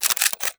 CAMERA_Shutter_01_mono.wav